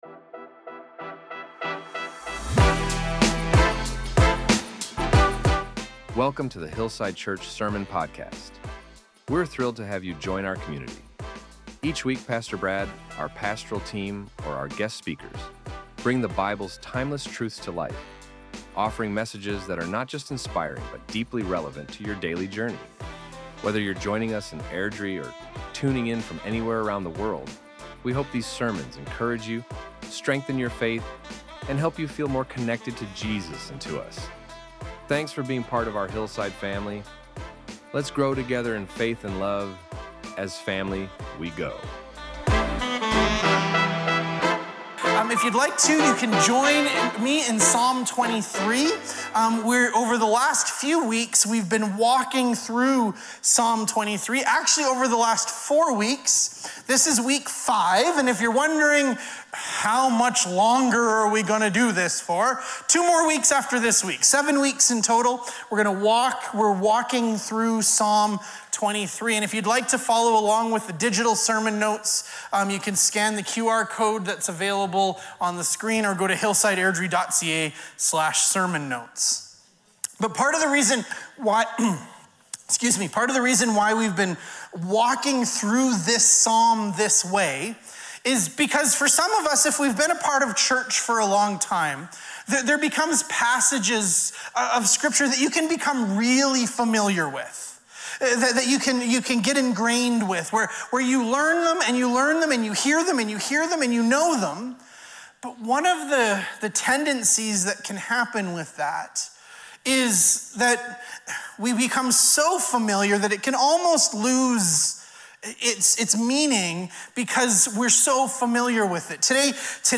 This sermon invited us to consider where we turn when life feels unstable.